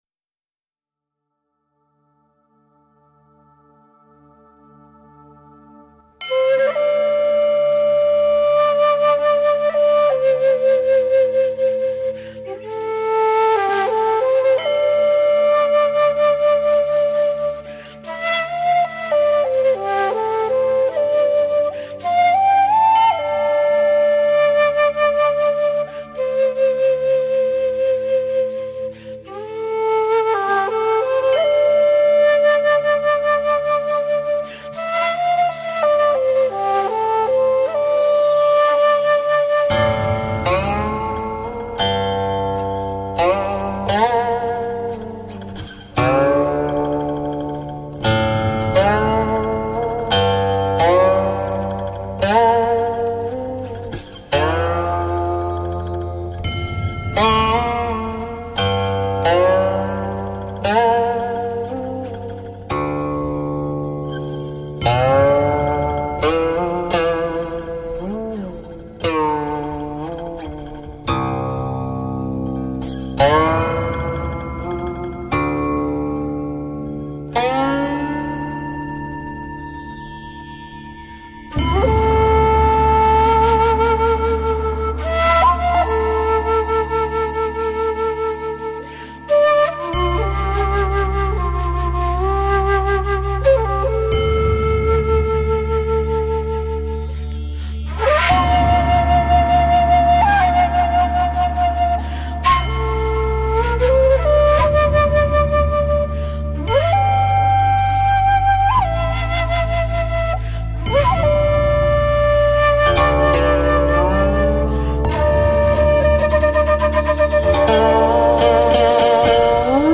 箫/排箫/笛子
琵琶
小提琴
大提琴
编钟
本专辑十个曲目的灵感都是来自古代诗词，透过古琴弹奏，让古今情怀穿越。